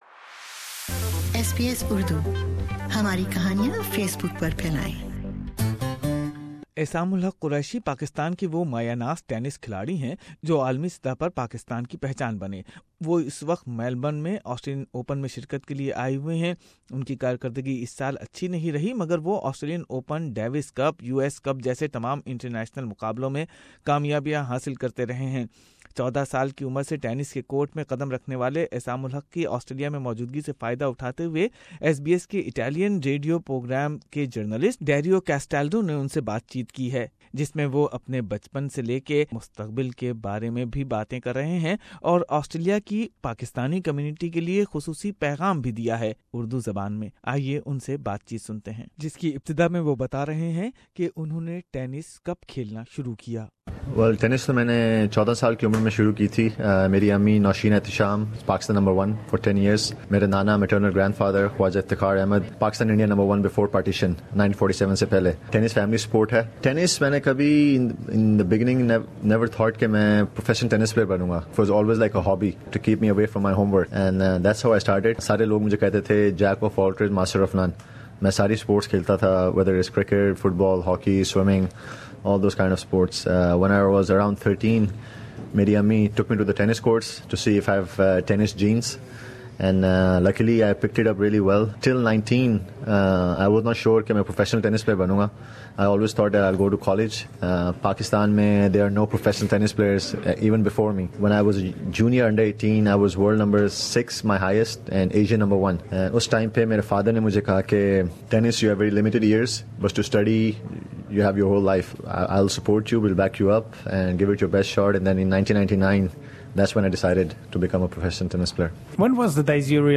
Pakistani Tennis star Aisam Ul Haq Qureshi requested Australian Pakistani community to keep him remember in their prayers..An Exclusive interview of Aisam during Australian Open where he talks about his childhood, his role as goodwill Ambassador for the UN, the struggle of traveling on a Pakistani passport after 9/11 besides his career goals. He also shared his views why he choose Indian and Israeli players as his partners.